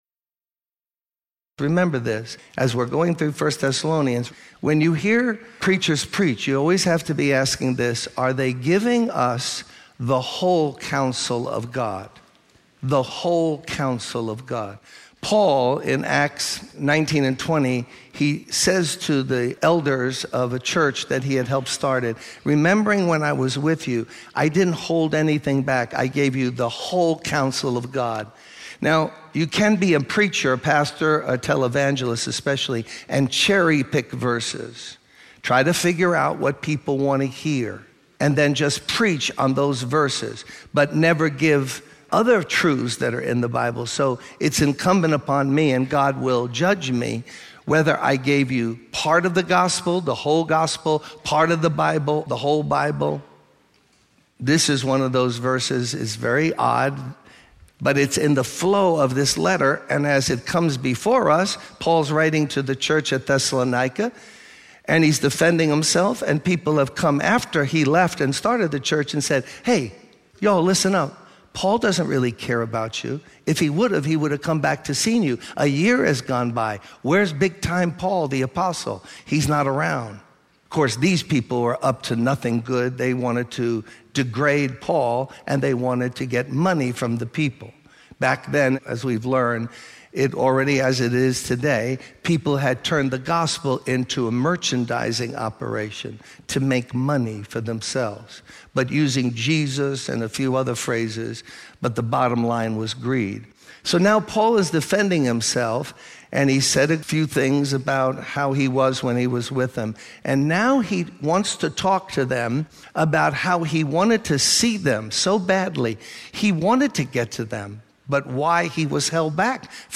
In this sermon, the speaker emphasizes the importance of being a soldier for God and facing discouragements and hindrances with determination. He shares personal experiences of facing discouragement and wanting to quit, but ultimately persevering.